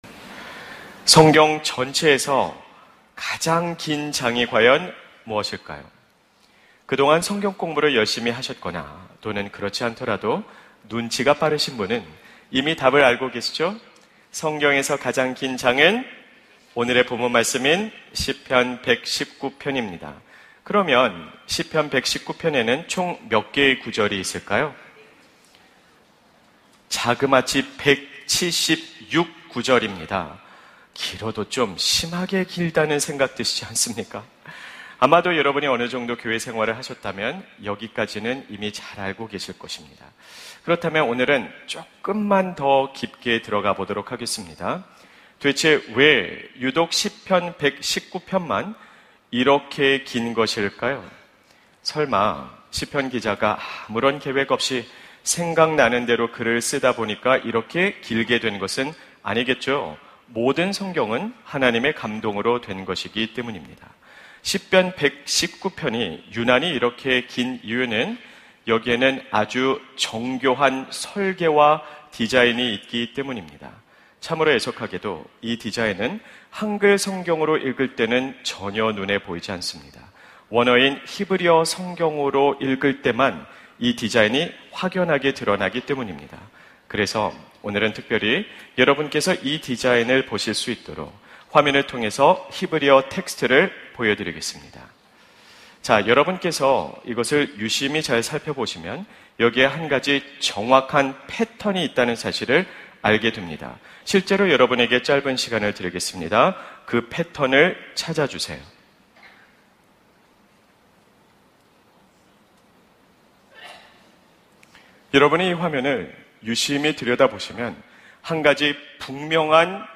설교 : 주일예배 내 삶에 하나님의 말씀이 살아 숨 쉬는가?